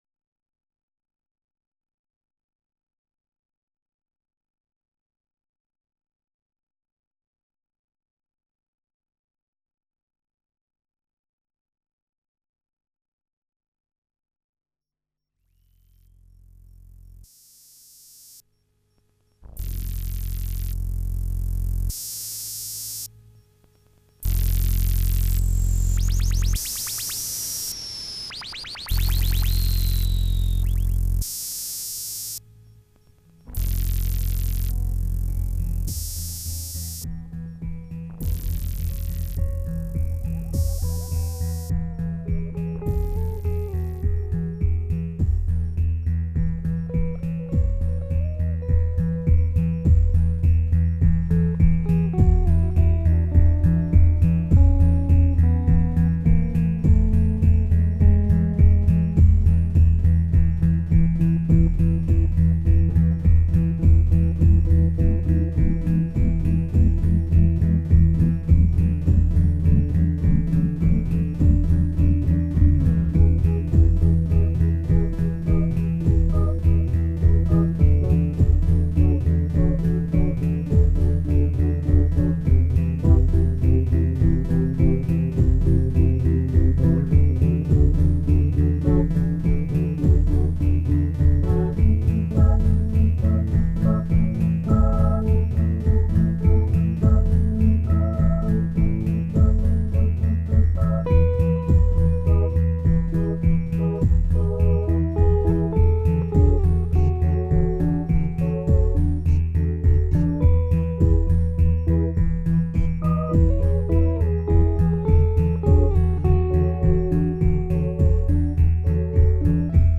Sound Sample: At 3min 33 sec the sound you hear is being controlled by a guitar courtesy of the RS35